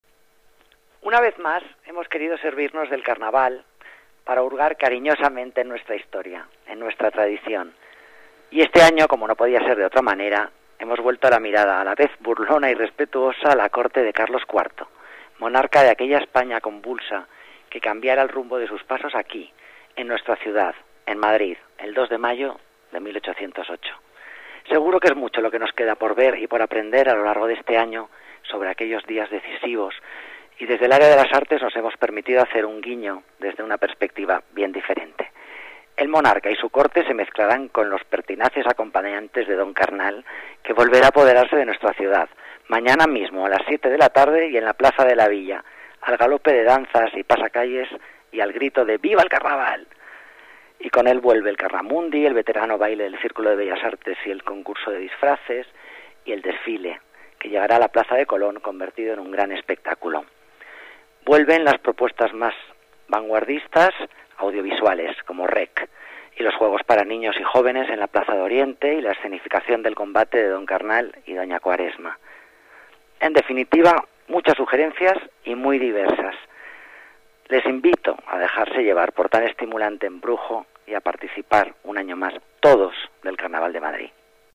Nueva ventana:Declaraciones de Alicia Moreno sobre el programa de Carnaval de la Ciudad de Madrid